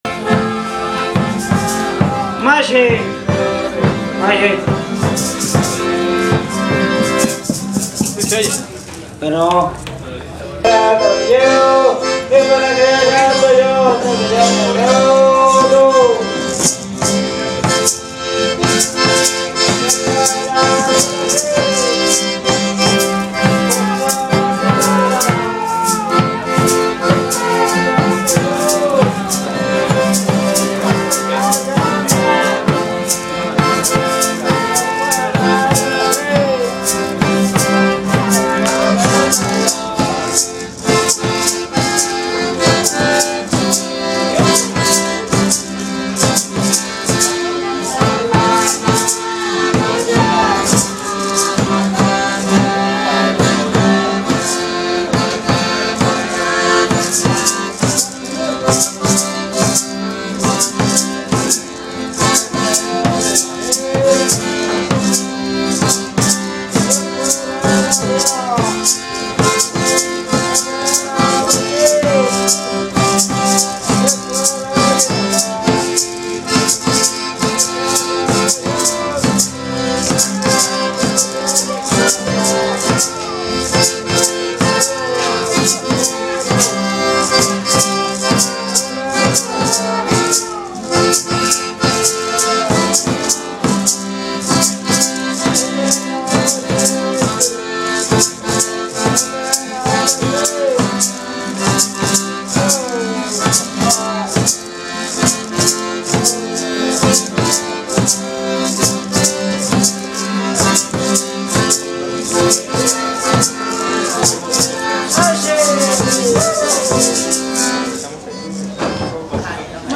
Músicos y danzantes de San Juan Chamula
se trata de músicos y danzantes de San Juan Chamula realizada al termino de una conferencia sobre el Carnaval Chamula, en un cafe bar de San Cristobal de Las Casas, Chiapas.
Equipo: Ipod Touch 4g